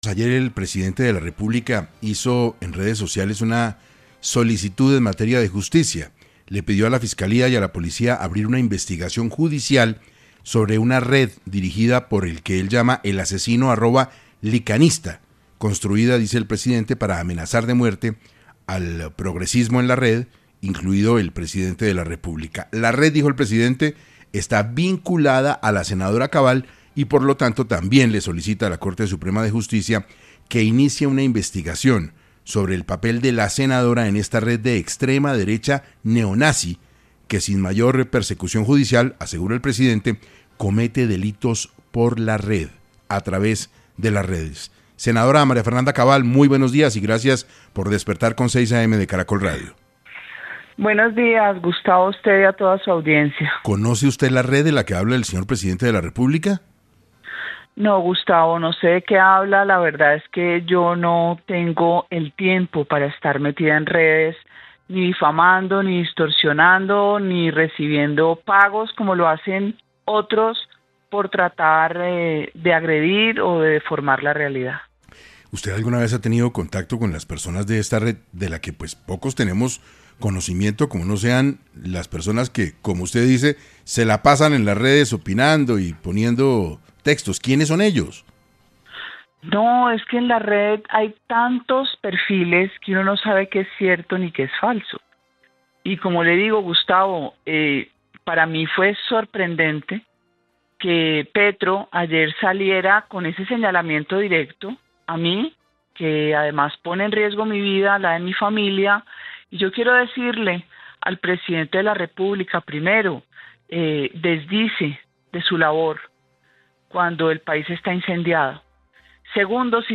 La senadora se refirió en 6AM a las acusaciones del presidente por supuestamente hacer parte de una red, que se encarga de amenazar al progresismo.
Buscando aclarar los hechos, la senadora habló para 6AM, y desmintió con argumentos las acusaciones del presidente.